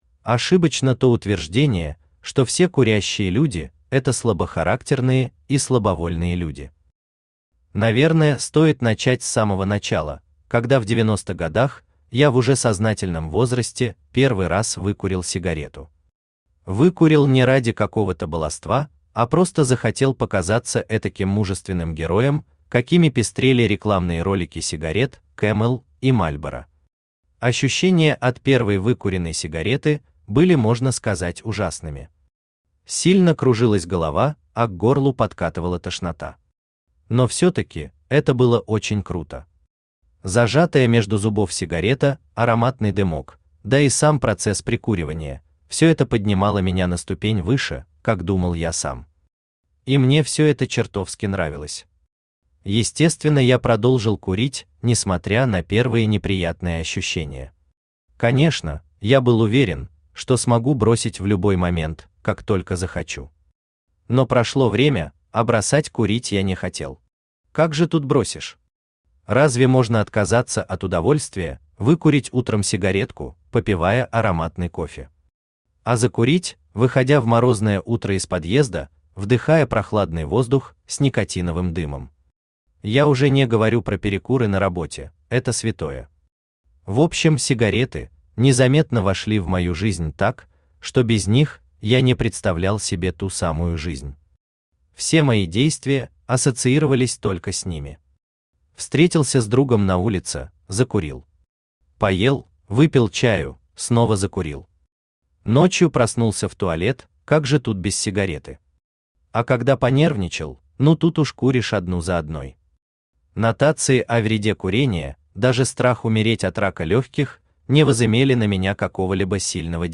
Аудиокнига Я бросил курить | Библиотека аудиокниг
Aудиокнига Я бросил курить Автор Oleg Bertov Читает аудиокнигу Авточтец ЛитРес.